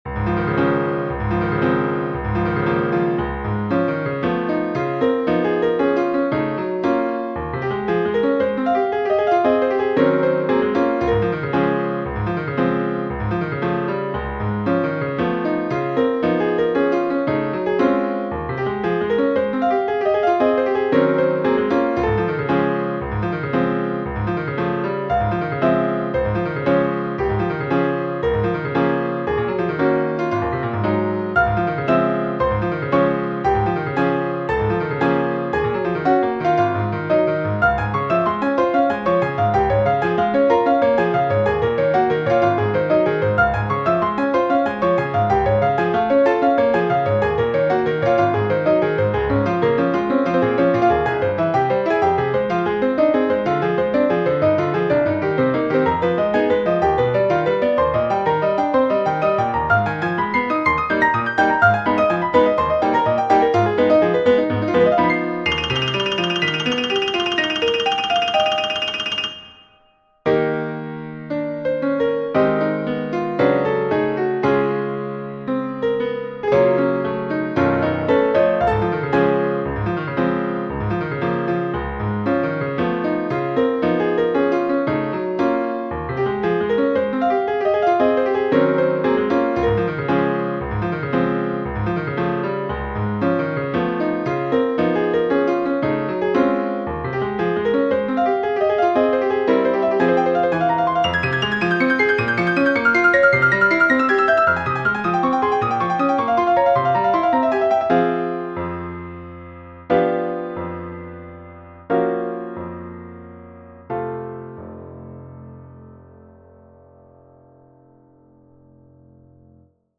For solo Piano: